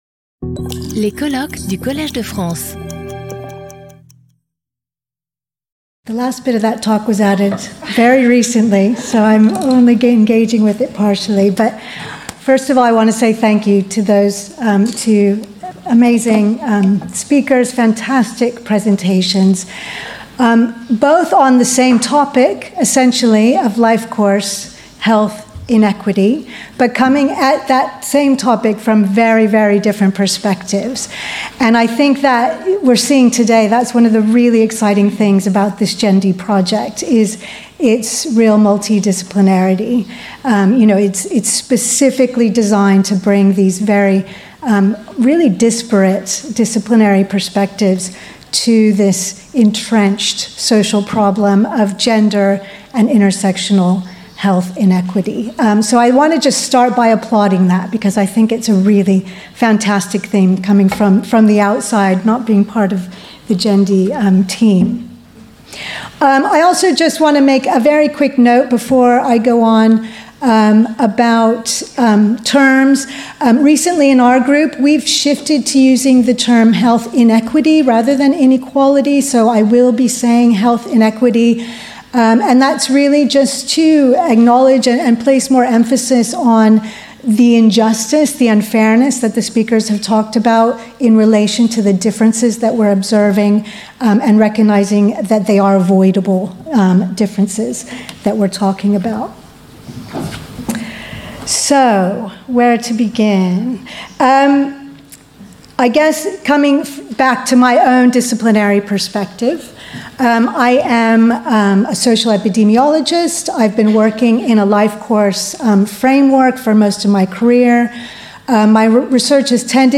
Discussion | Collège de France
Skip youtube video player Listen to audio Download audio Audio recording This video is offered in a version dubbed in French.